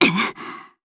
voices / heroes / jp
Valance-Vox_Landing_jp.wav